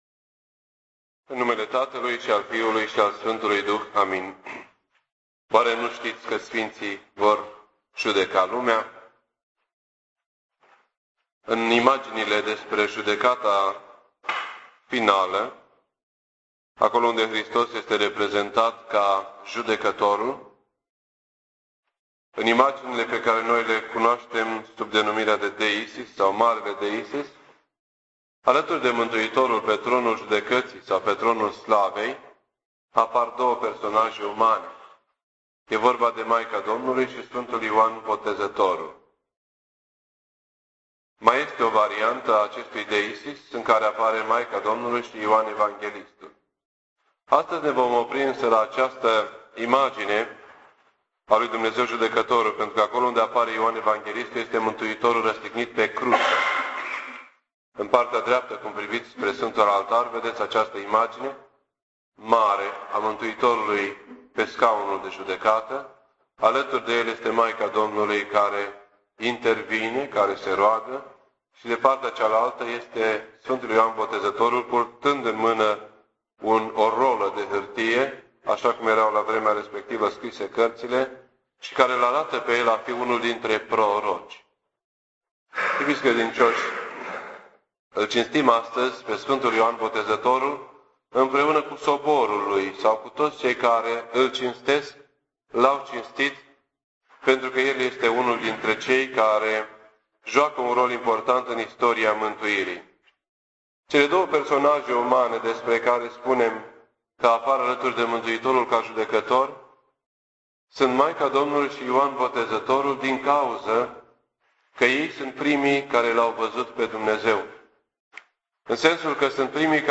This entry was posted on Monday, January 7th, 2008 at 9:54 AM and is filed under Predici ortodoxe in format audio.